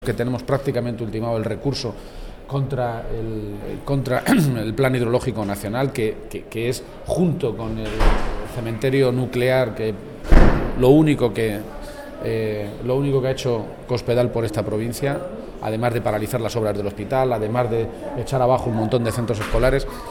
García-Page se pronunciaba de esta manera esta mañana, en Cuenca en una comparecencia ante los medios de comunicación minutos antes de que comenzara la reunión de un Comité Provincial extraordinario del PSOE de esa provincia.
Cortes de audio de la rueda de prensa